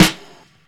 Medicated Snare 27.wav